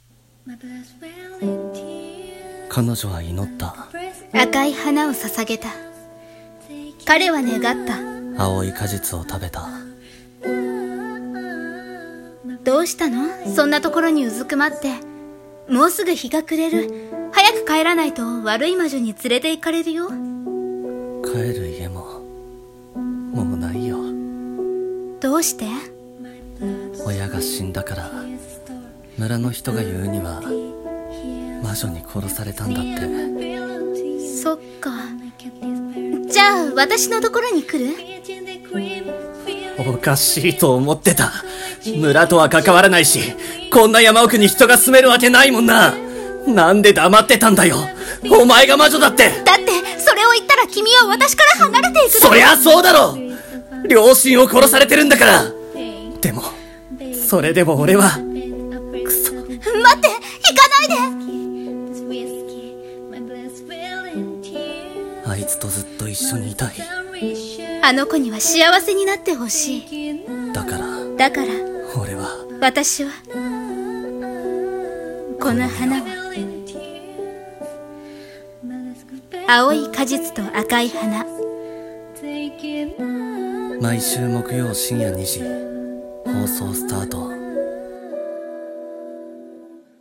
青い果実と赤い花【CM予告風】